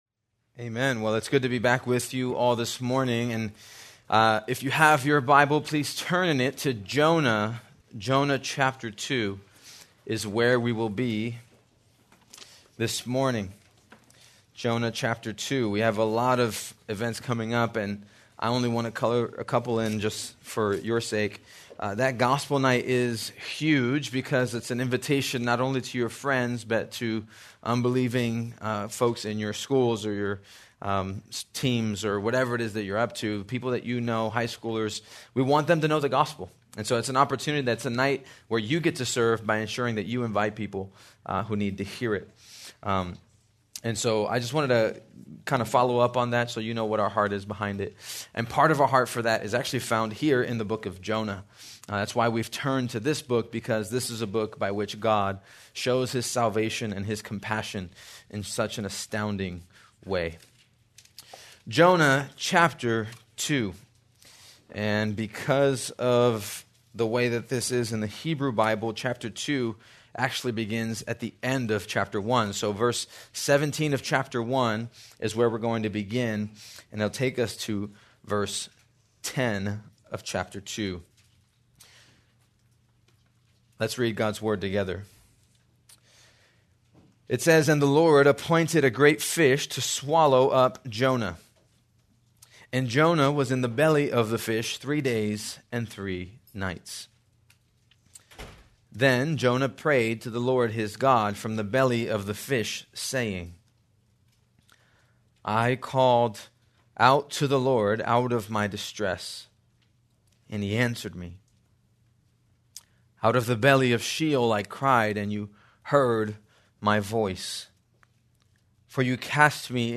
April 6, 2025 - Sermon | 180 Ministry | Grace Community Church